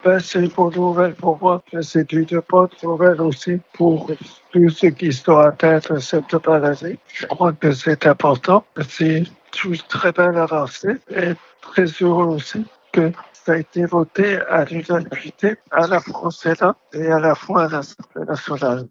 En février dernier, il avait réagi à l’adoption de cette loi, sur Chérie FM Vallée du Rhône.